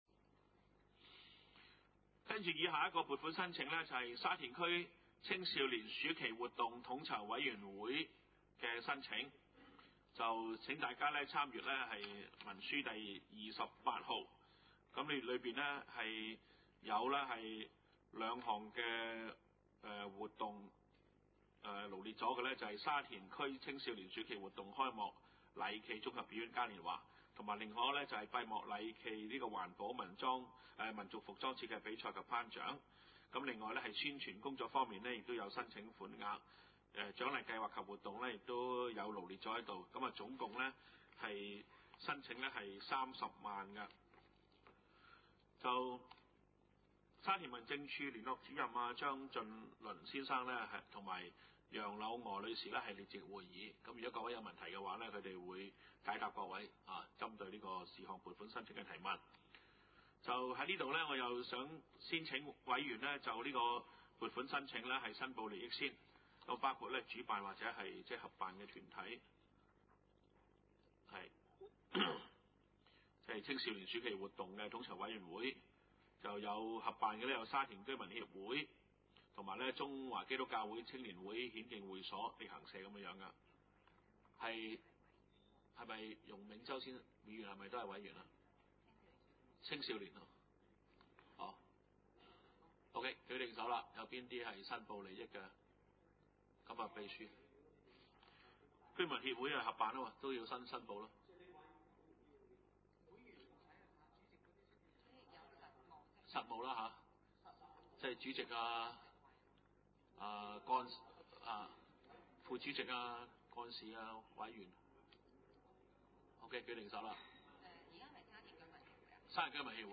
二零零八年第二次會議
: 沙田區議會會議室